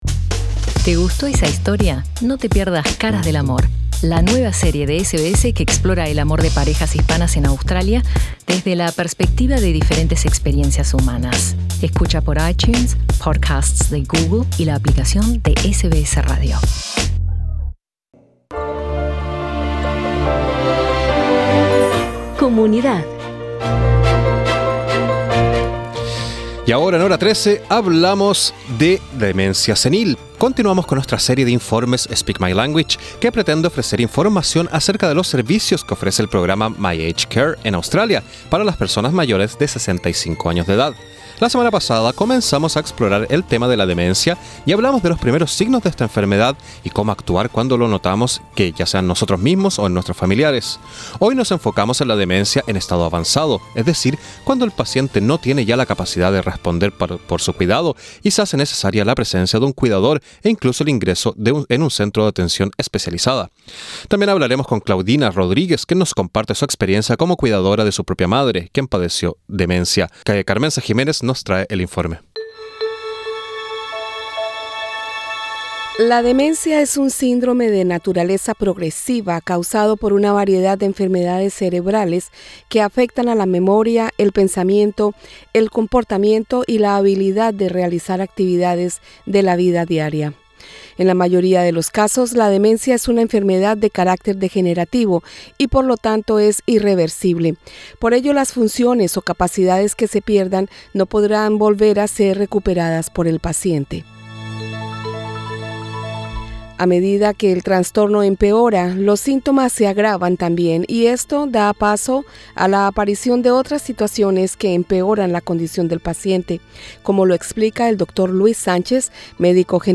Escucha arriba el informe con entrevistas a especialistas y personas con familiares que padecen demencia.